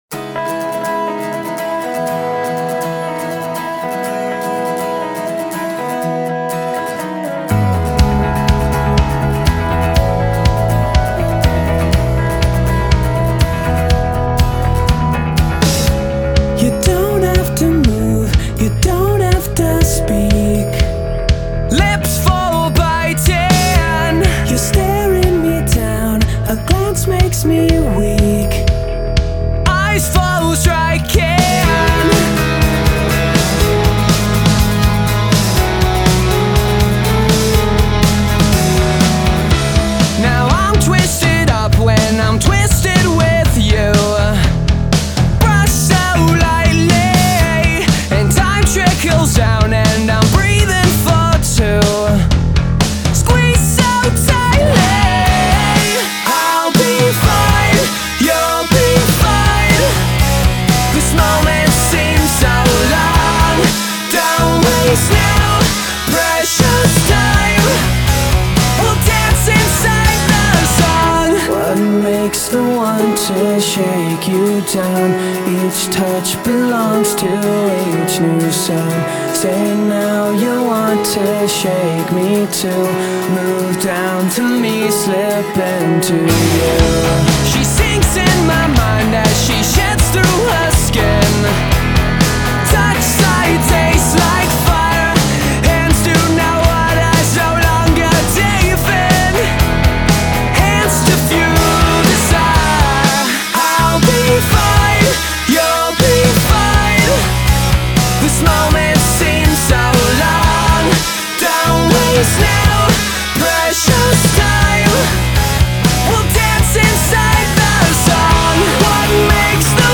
Тема: поп-рок